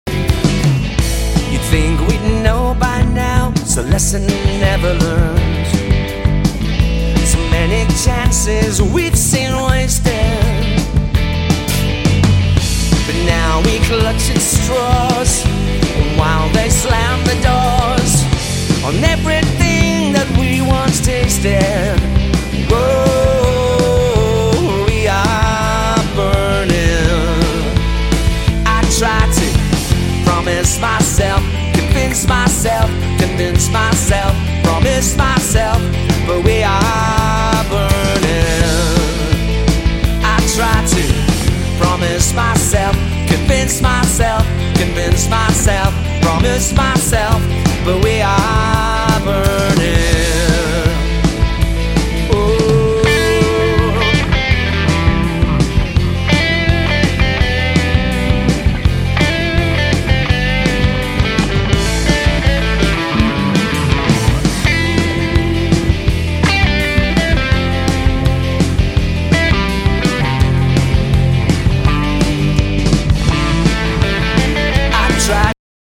abotsa, gitarrak eta teklatuak
baxua
bateria, perkusioak eta abotsak